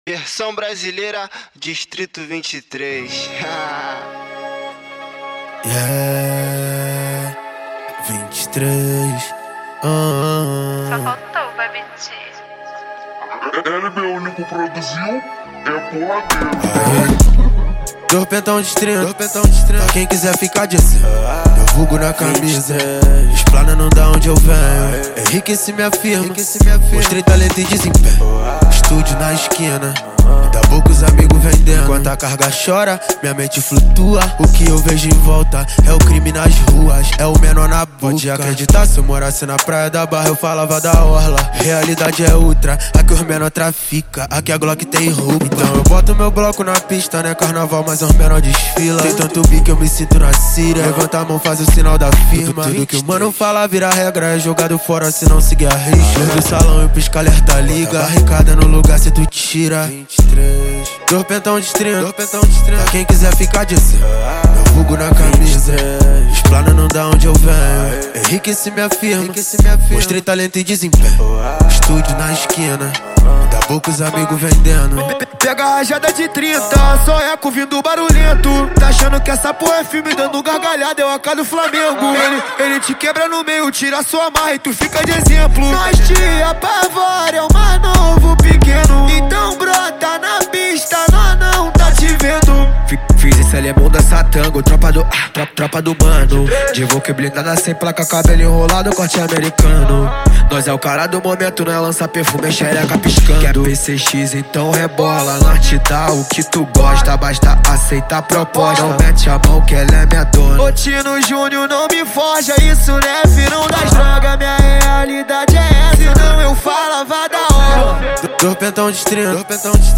2024-03-03 09:45:21 Gênero: Trap Views